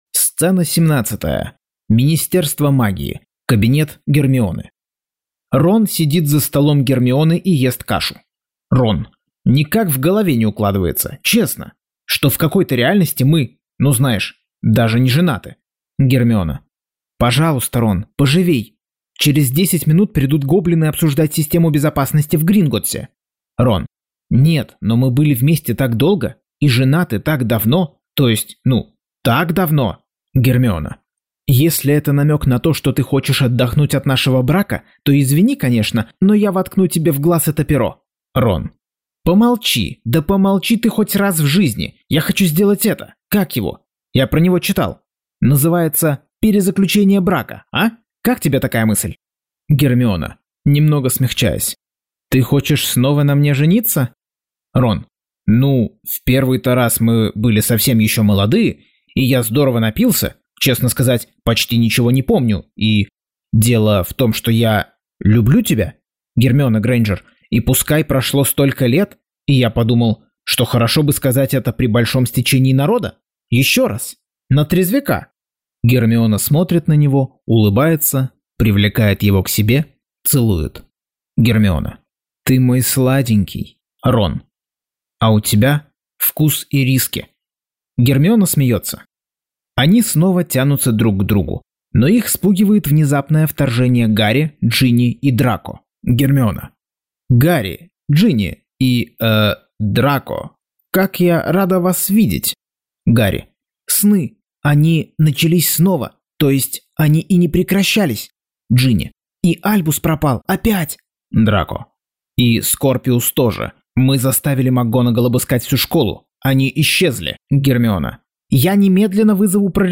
Аудиокнига Гарри Поттер и проклятое дитя. Часть 49.